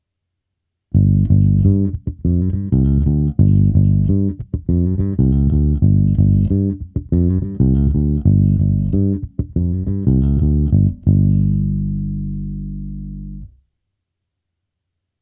zvuk samotné simulace aparátu.
To už má značný charakter, tlak, trochu toho prostoru, příjemný měkký sametový sound, ale zase něco chybí. Zvuk je trochu zastřený, postrádá výšky.